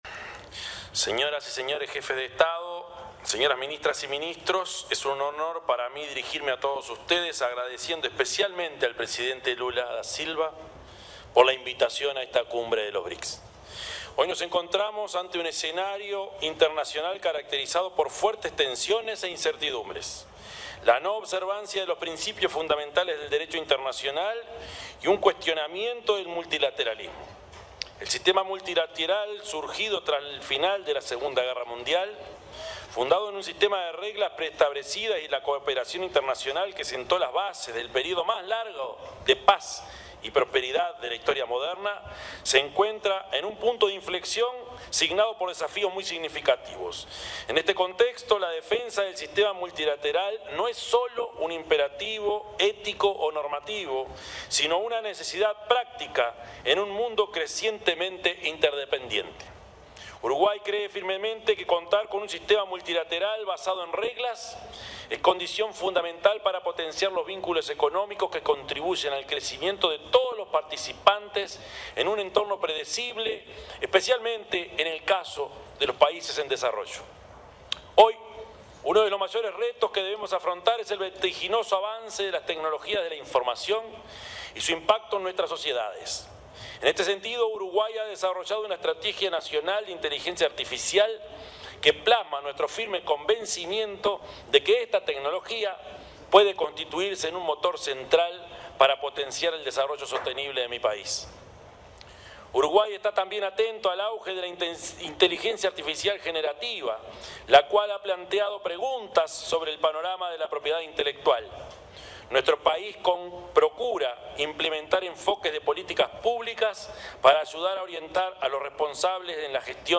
Palabras del presidente Yamandú Orsi en la 17.ª Cumbre de BRICS